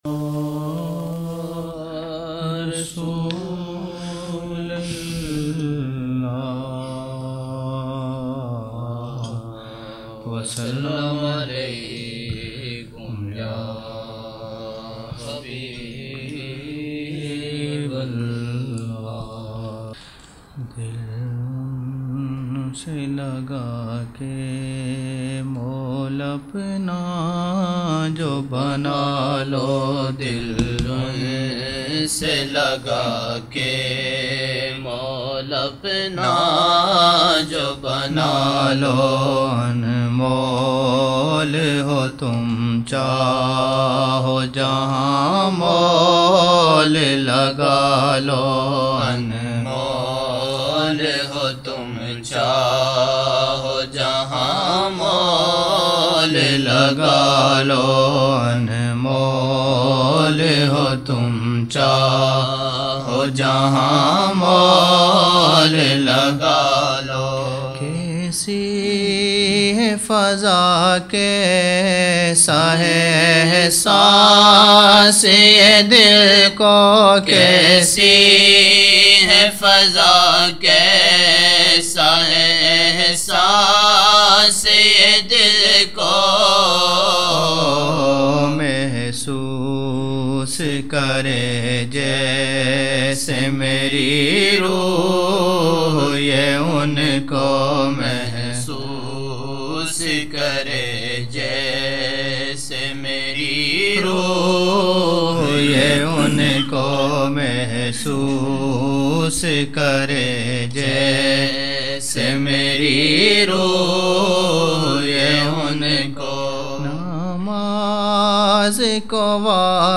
12 November 1999 - Assar mehfil (4 Shaban 1420)
Naat Shareef